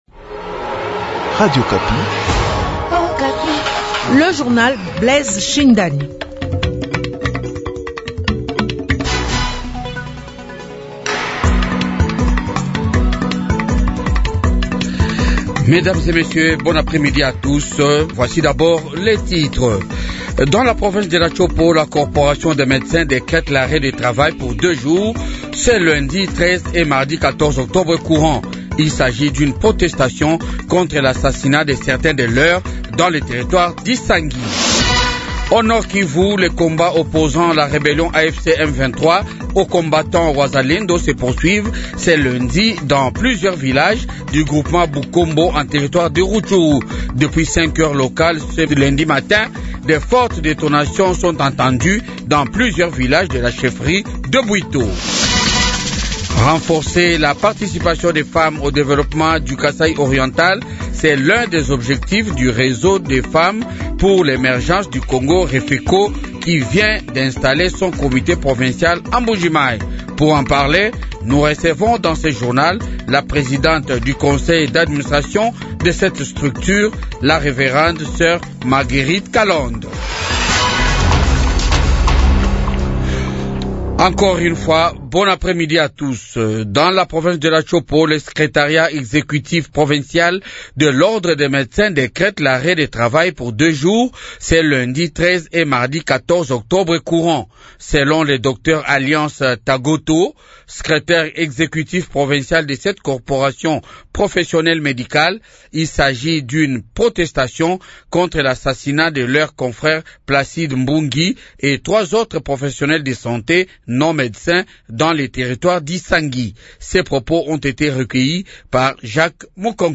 journal de 15h